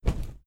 Flap Wing 2.wav